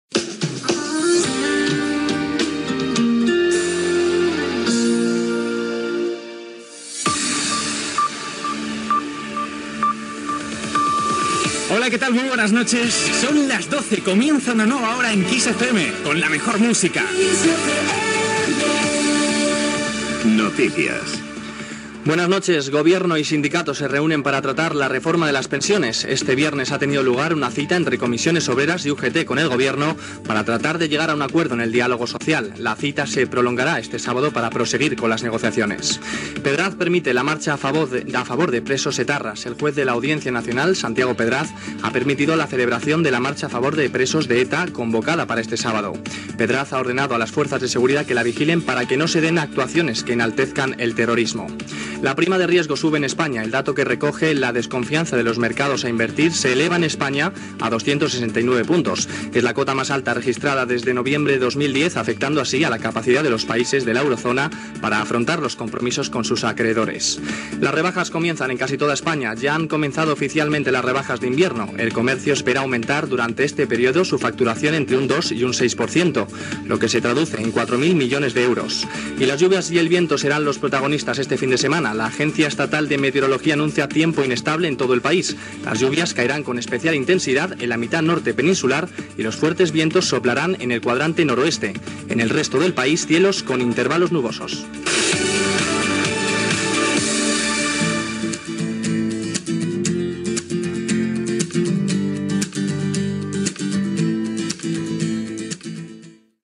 Hora, careta, resum informatiu del dia i de l'endemà
Informatiu